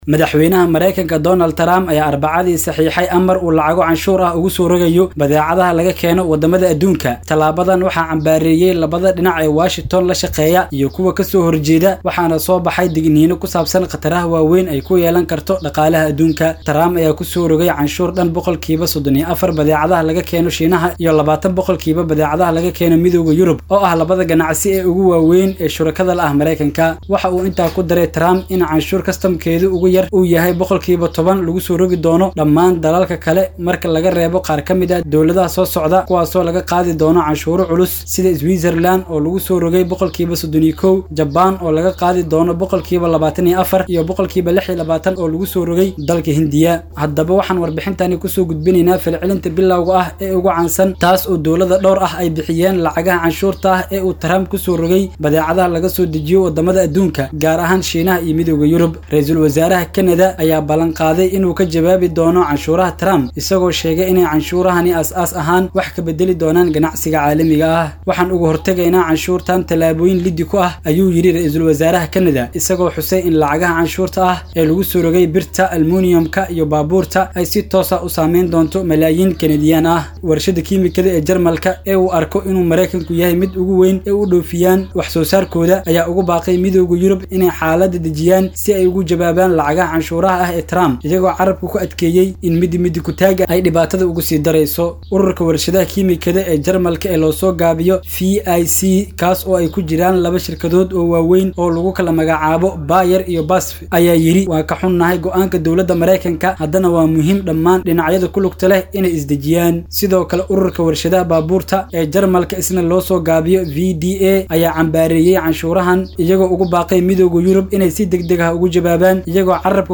Warbixin-Falcelinnada-Canshuuraha-Trump.mp3